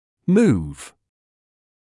[muːv][муːв]двигать, перемещать; двигаться, перемещаться